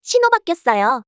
traffic_sign_changed.wav